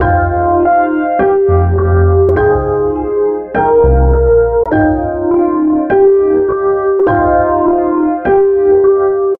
Tag: 51 bpm Hip Hop Loops Synth Loops 1.58 MB wav Key : Unknown Ableton Live